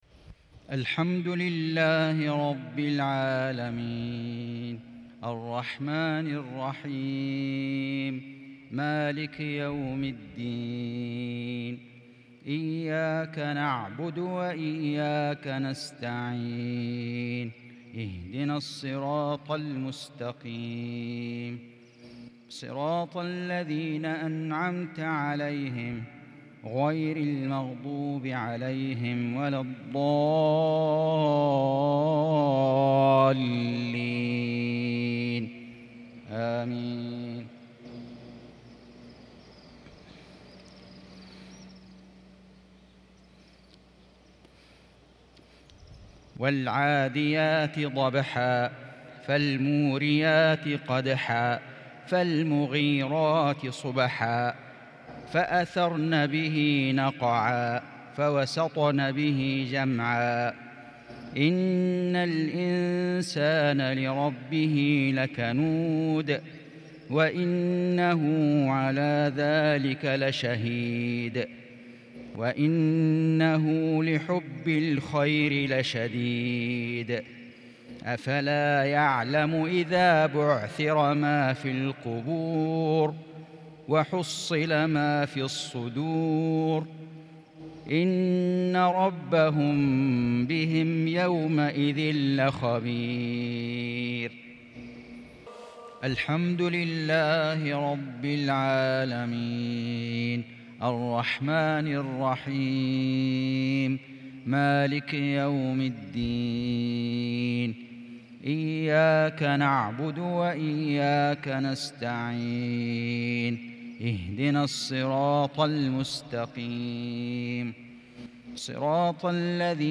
مغرب الاثنين 5 شوال 1442هــ من سورتي العاديات و الهمزة | Maghrib prayer from Surat Al-Adiyat and Humazah 17/5/2021 > 1442 🕋 > الفروض - تلاوات الحرمين